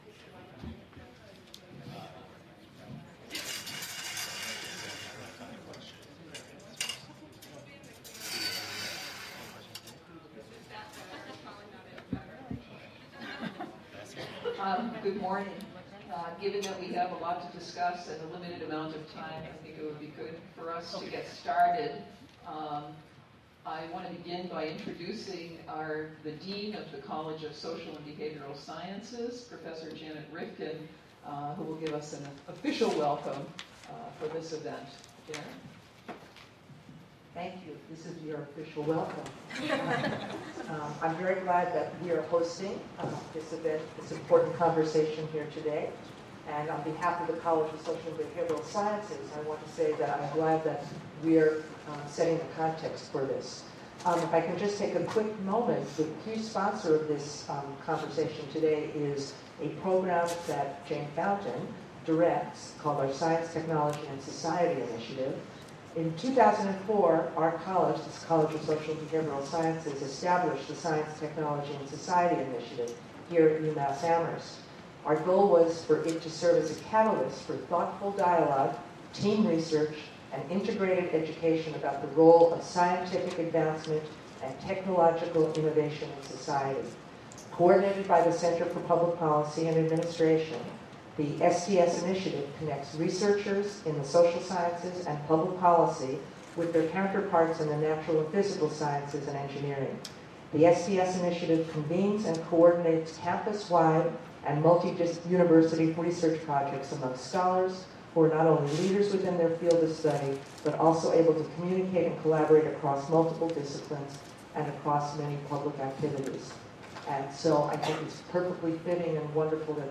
Town Hall Forum: Broadband in Massachusetts [Video]